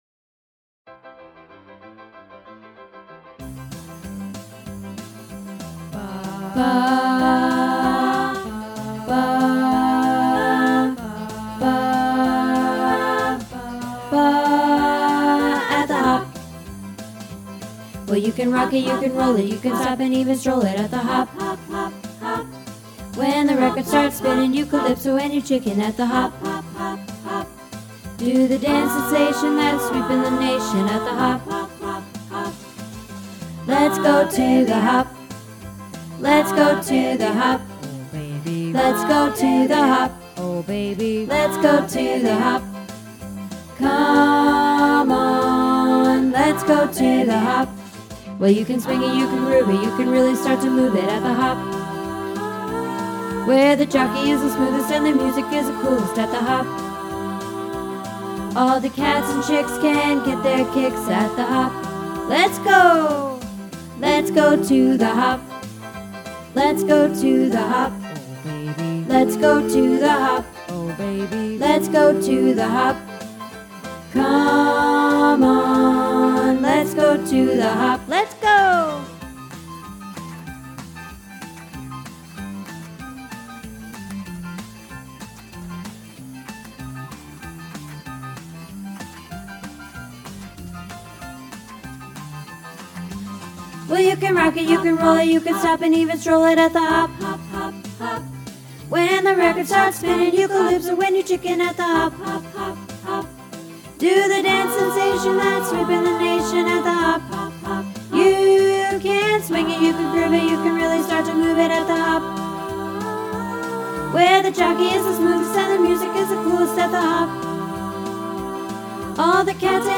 At the Hop – Practice | Happy Harmony Choir